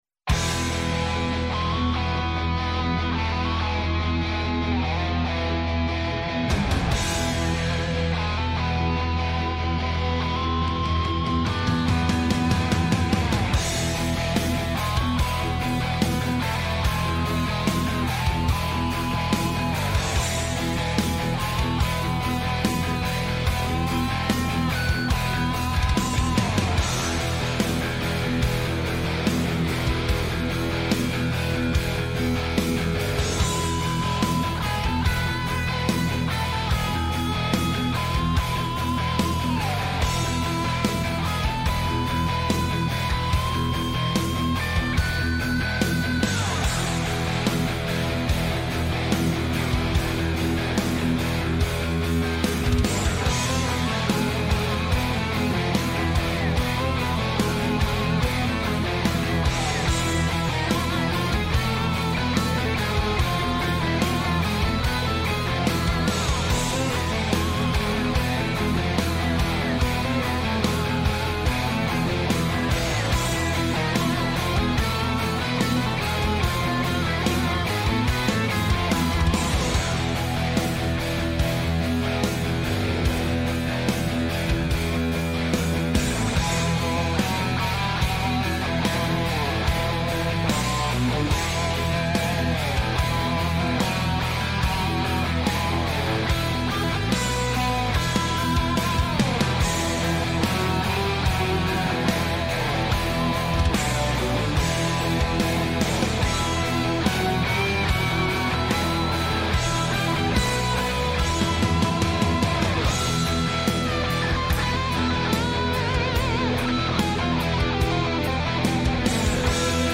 Επιτρέπεται μάλιστα η είσοδος στο στούντιο σε κάθε λογής περαστικούς!